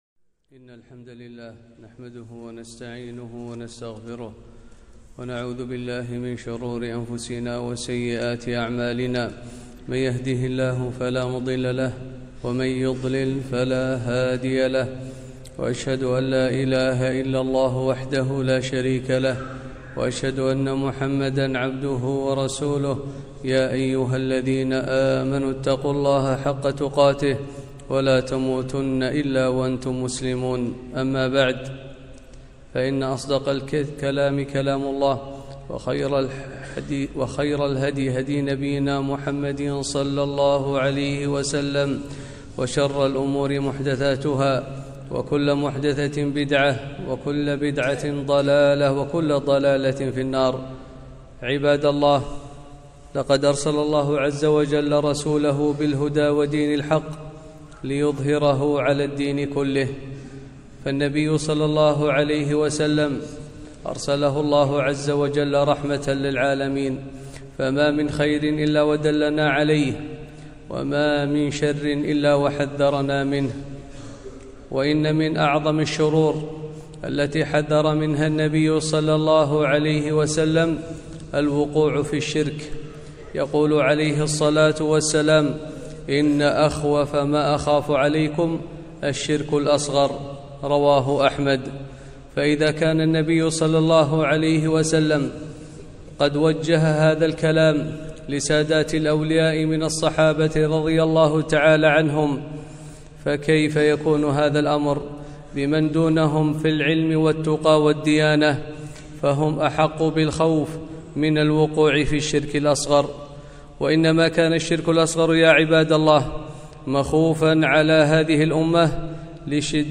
خطبة - الشرك الأصغر وصوره